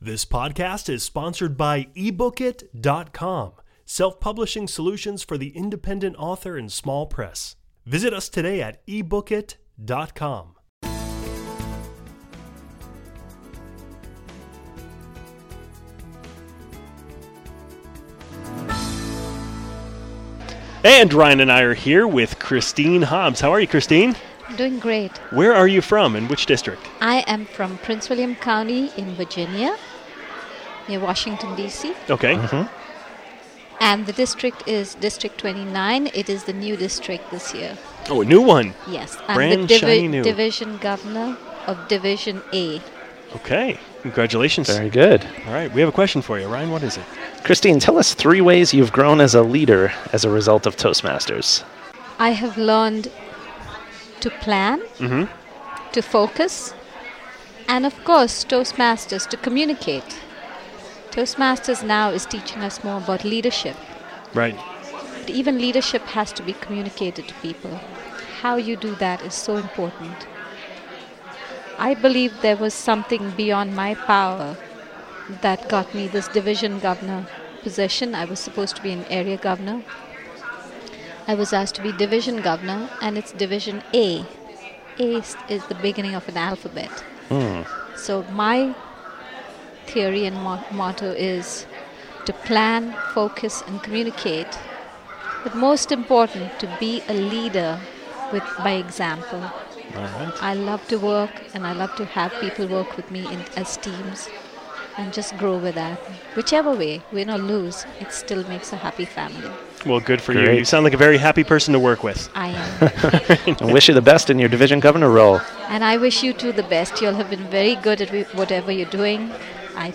#049: Toastmasters International 2011 Convention - Live Interviews | Toastmasters Podcast